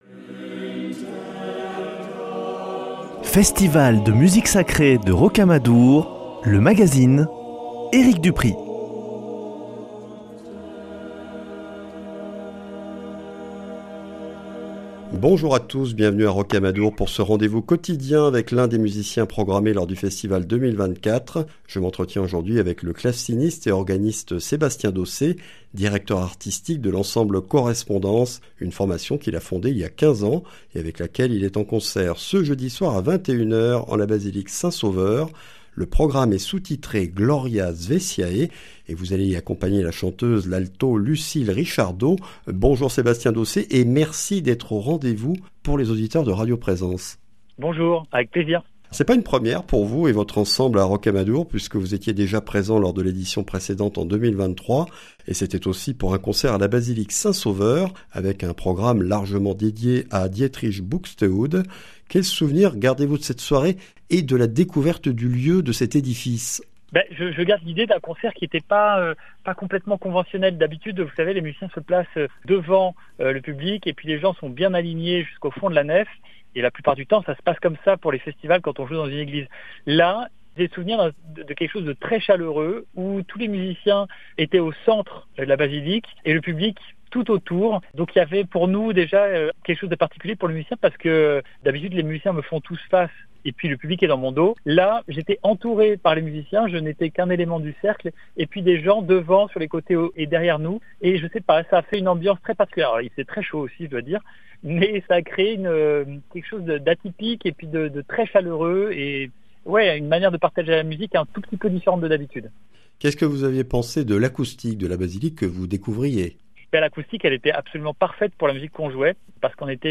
ITW